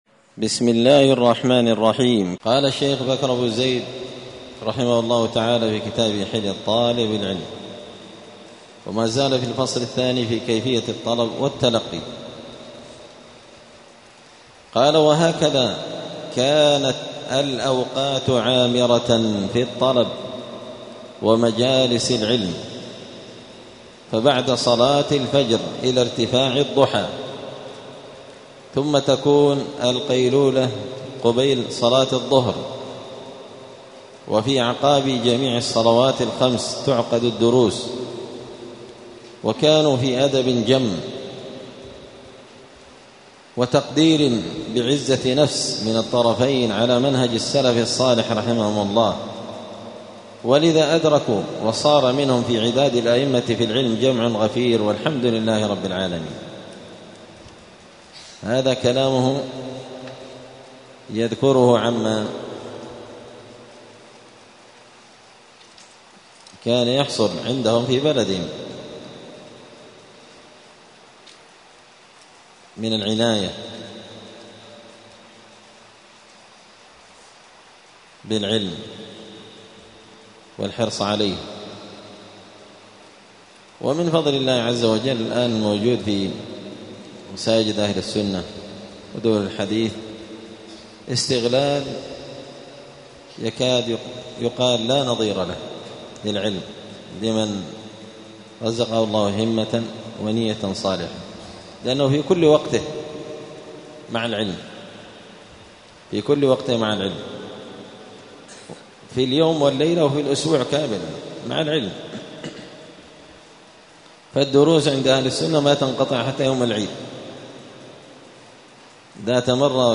الأربعاء 21 محرم 1447 هــــ | الدروس، حلية طالب العلم، دروس الآداب | شارك بتعليقك | 7 المشاهدات